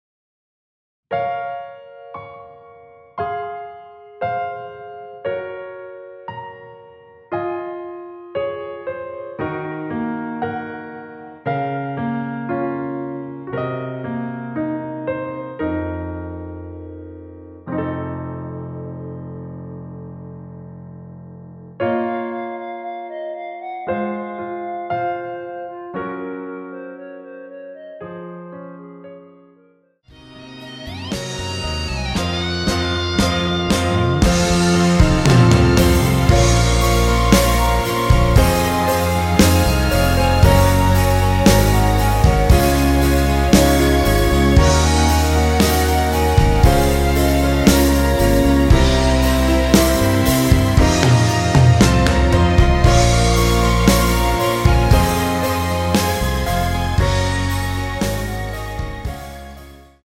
원키에서(+3)올린 여성분이 부르실수 있는키의 멜로디 포함된 MR입니다.
Bbm
노래방에서 노래를 부르실때 노래 부분에 가이드 멜로디가 따라 나와서
앞부분30초, 뒷부분30초씩 편집해서 올려 드리고 있습니다.
중간에 음이 끈어지고 다시 나오는 이유는